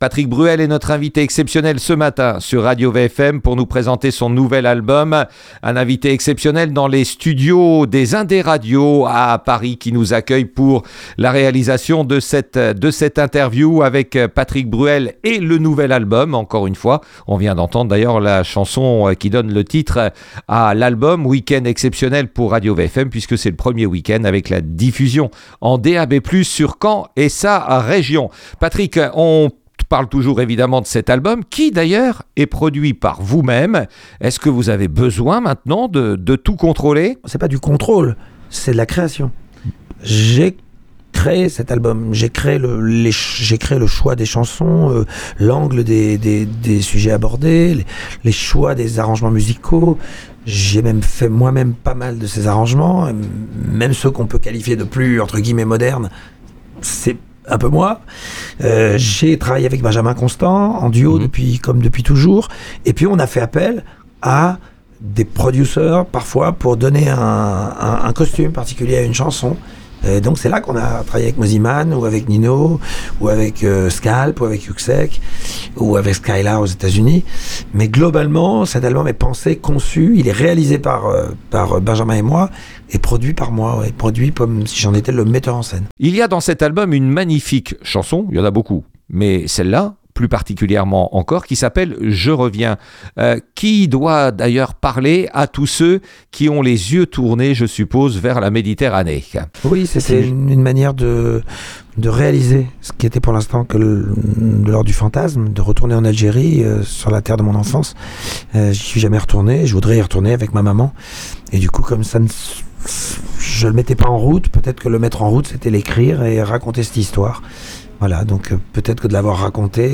Interview de Patrick Bruel sur RadioVFM